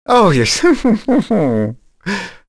Fluss-Vox_Happy2.wav